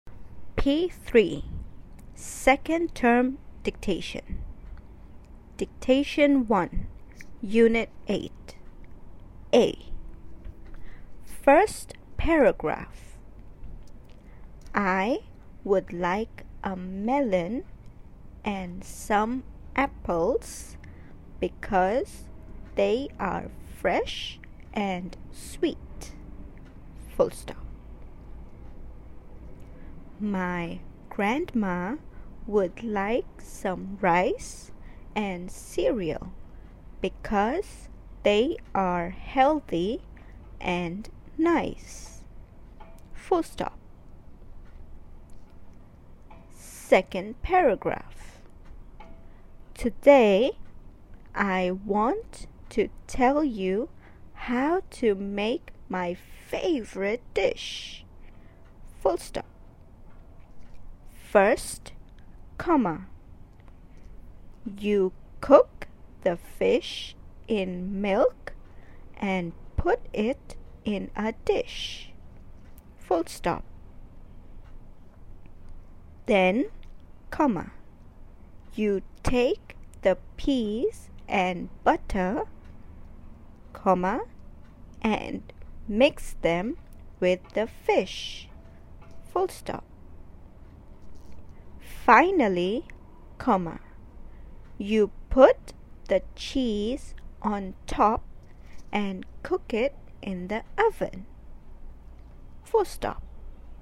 A. Seen Dictation (90%)